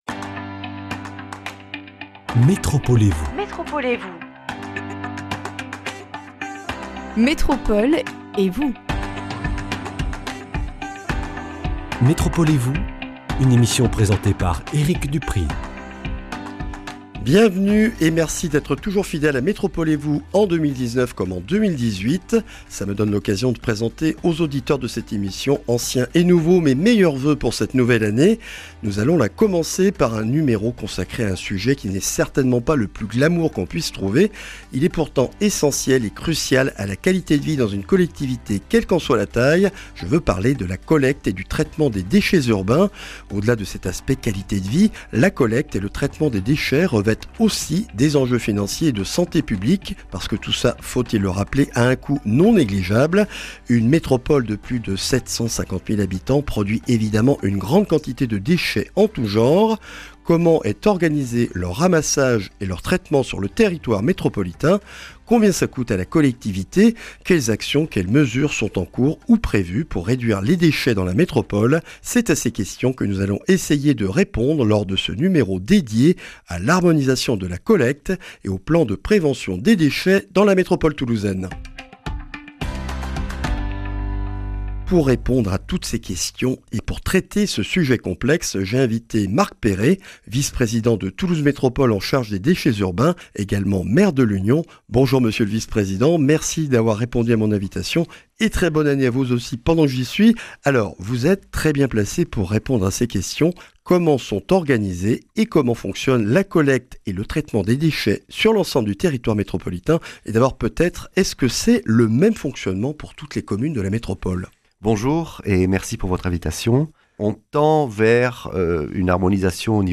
Comment sont collectés et retraités les déchets dans la métropole toulousaine ? Un point avec Marc Péré, maire de l’Union, Vice-président de Toulouse Métropole en charge des Déchets urbains. Organisation et harmonisation de la collecte, Programme local de prévention des déchets (PLPD) sur le territoire de Toulouse Métropole sont au menu de ce numéro.